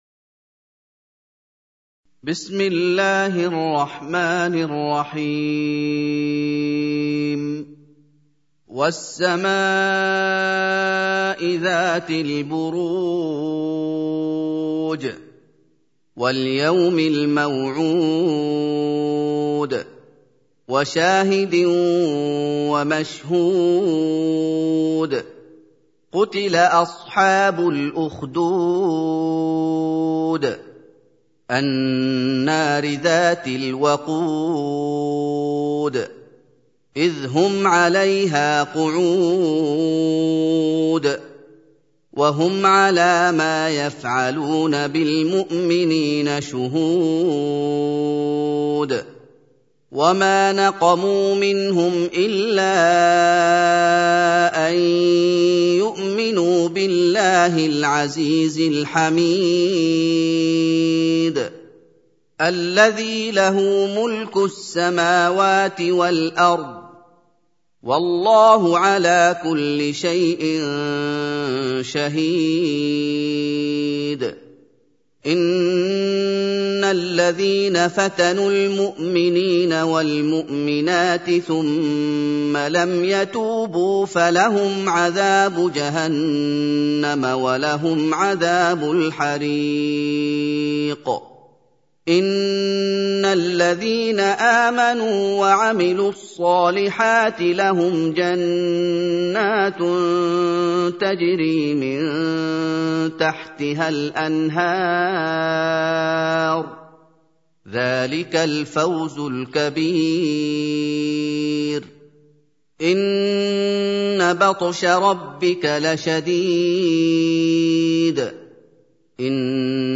سورة البروج | القارئ محمد أيوب